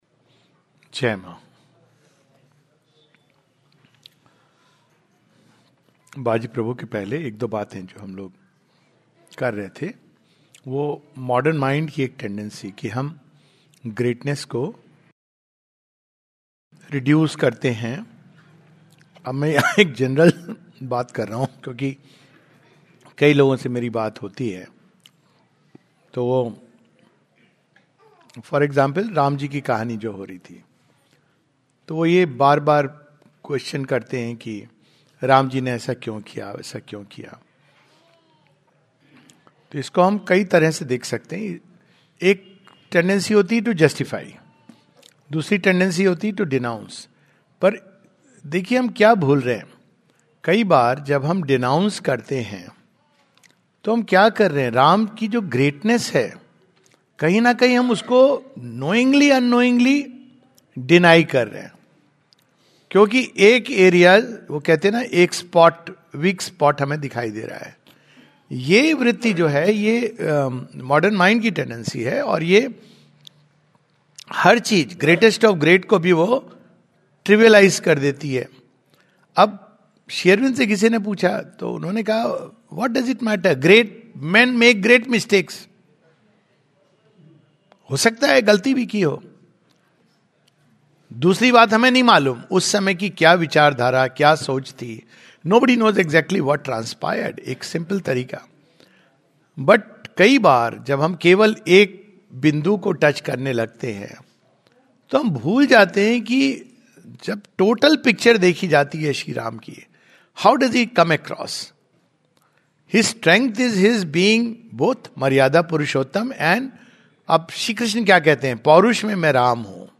Summary of Sri Aurobindo's poem. A talk